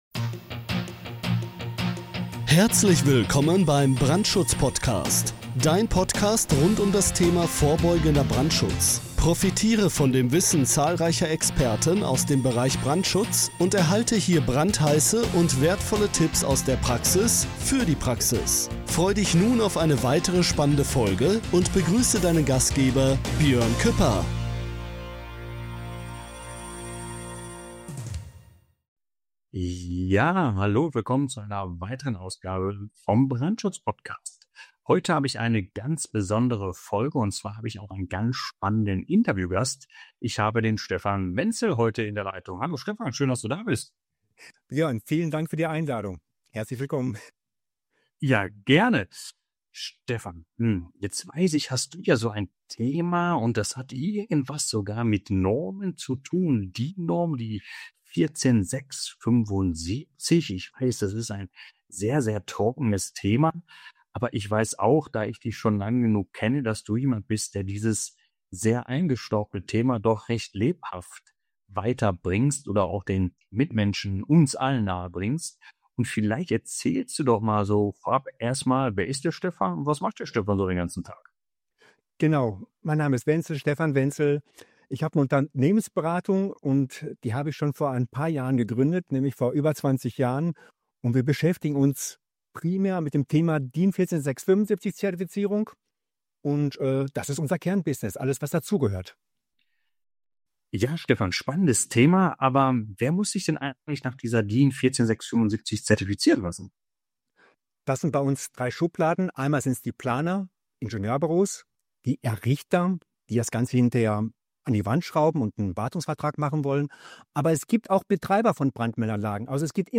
Experten Interview